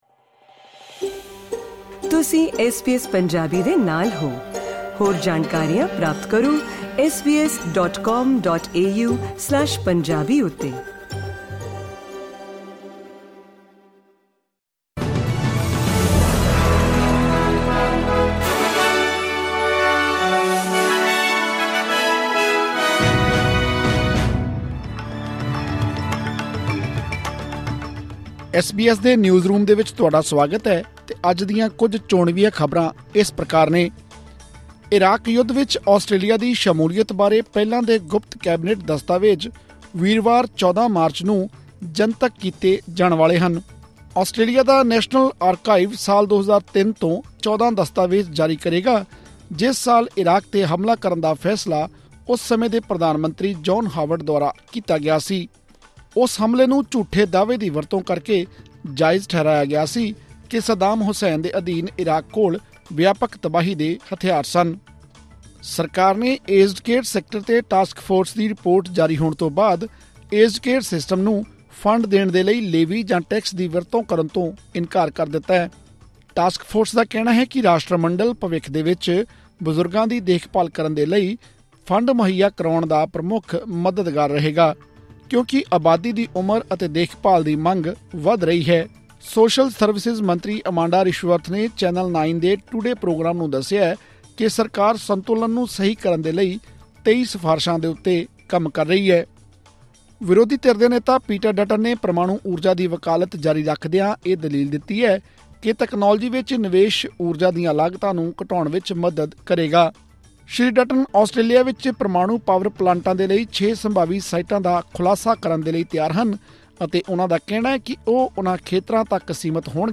ਐਸ ਬੀ ਐਸ ਪੰਜਾਬੀ ਤੋਂ ਆਸਟ੍ਰੇਲੀਆ ਦੀਆਂ ਮੁੱਖ ਖ਼ਬਰਾਂ: 12 ਮਾਰਚ, 2024